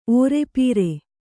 ♪ ōrepīre